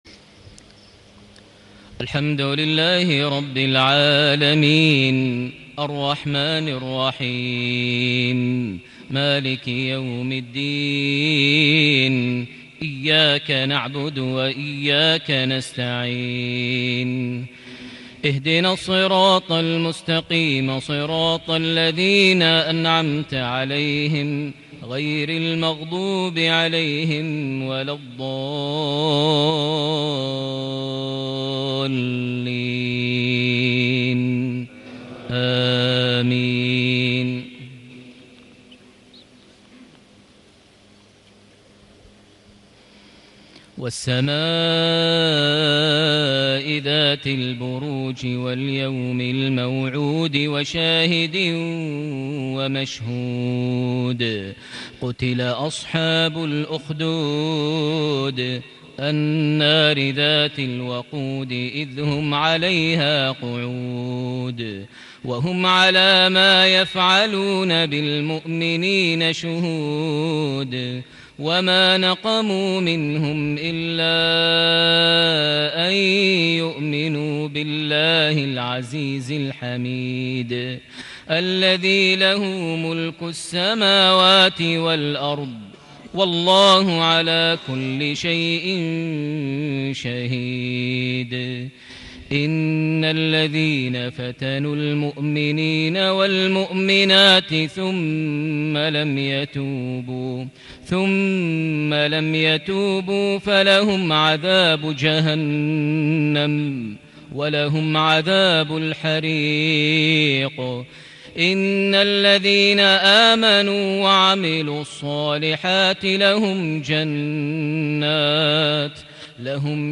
mghrip 5-2-2017 Surah Al-Burooj > 1438 H > Prayers - Maher Almuaiqly Recitations